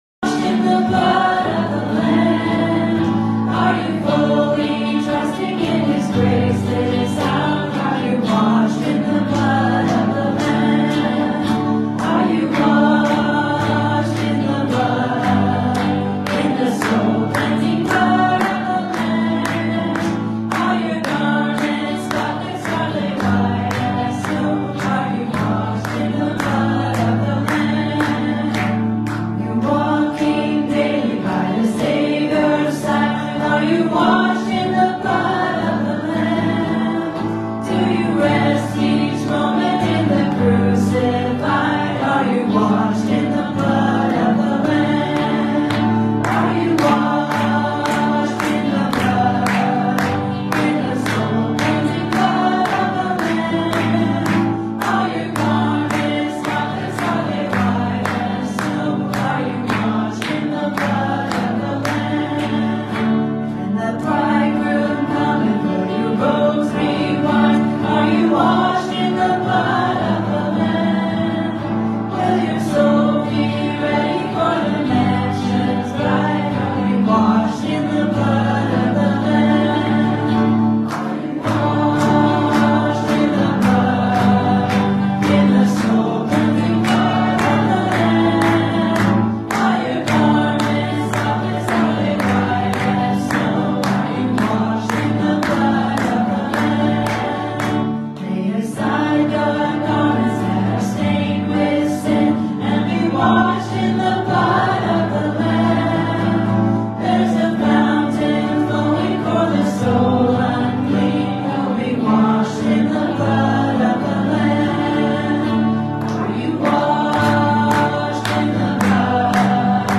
Passage: Romans 7:1-6 Service Type: Sunday Morning « Faith